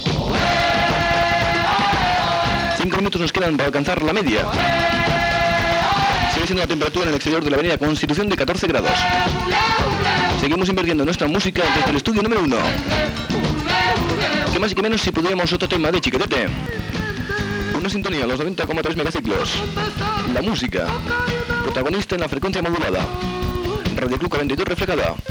5db423c0c9d6909dc2b461ca767783eef3d8dd45.mp3 Títol Radio Club 42 Emissora Radio Club 42 Titularitat Tercer sector Tercer sector Comercial Descripció Adreça de l'emissora, identificació i temes musicals. Gènere radiofònic Musical